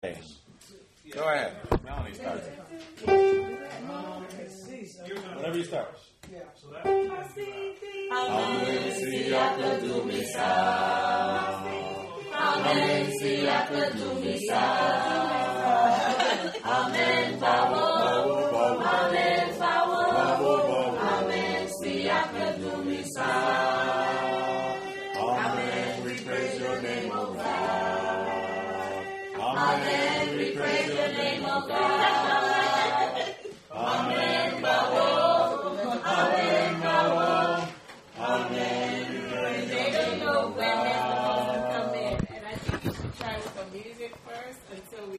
Amen, Siakudumisa PARTS: 5 parts (S-A-T-B + Soloist) with Piano (and Opt. Rhythm) Accompaniment RECORDING: Soprano Part “Masiti” Soloist Alto Part Tenor Part Bass Part SCORE: Amem Siakudumisa (Currently unavailable)